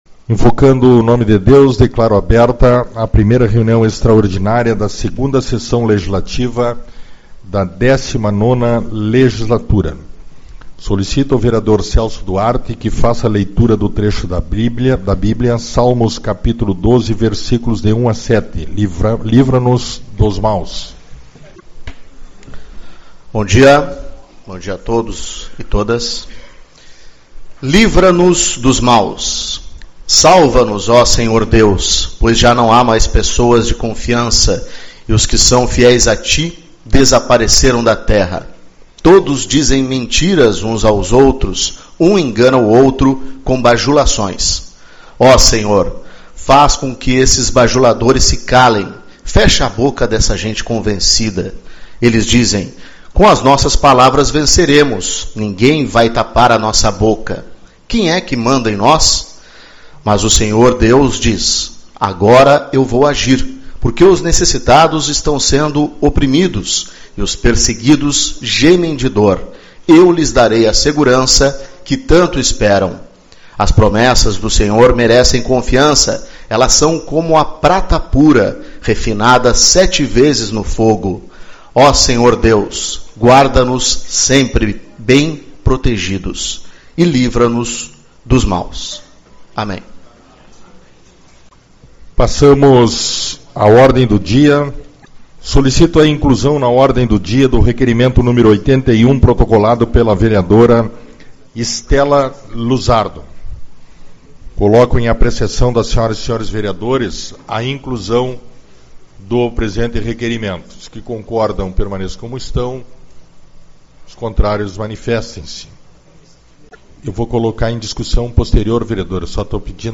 29/01 - Reunião Extraordinária